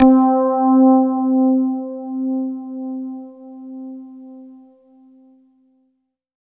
20 RHOD C4-R.wav